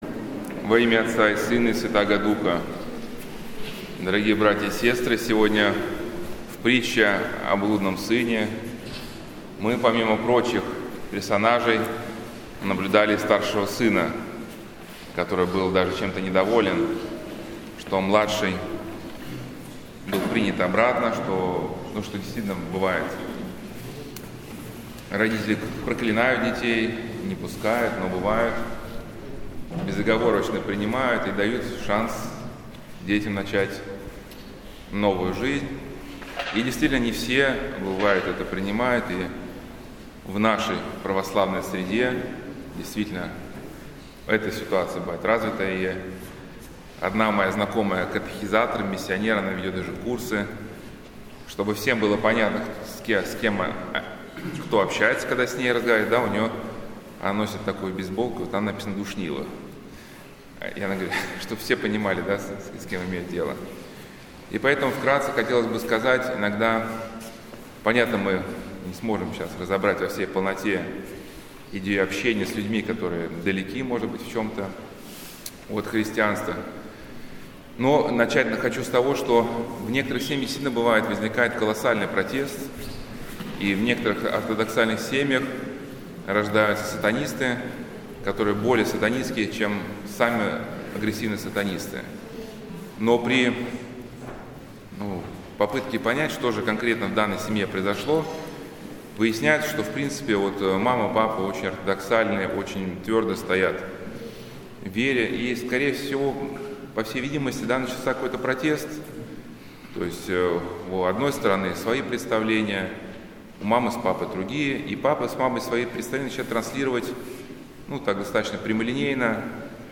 Аудиозапись проповеди